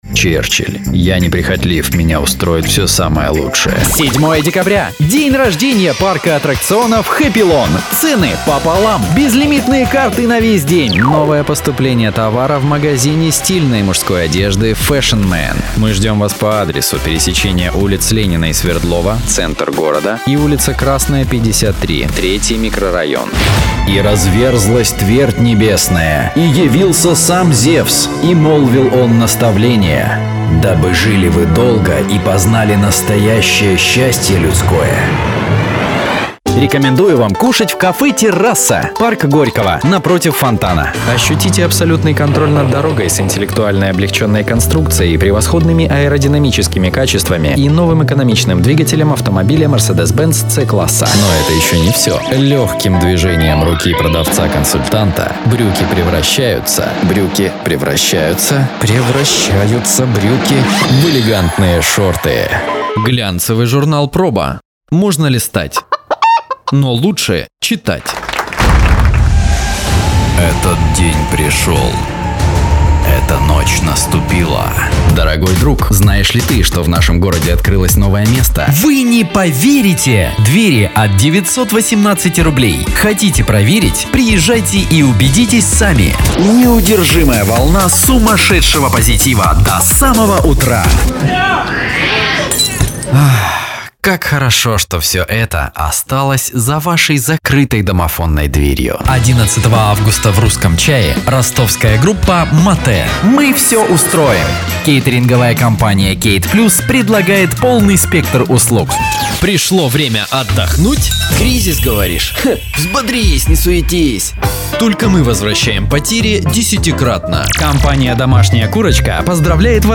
Пример звучания голоса
Муж, Другая
Rode NT1 Rode NTG2 ISK BM-800 Октава МК-012 ART Voice Channel Presonus StudioLive 16.0.2 ESI Juli@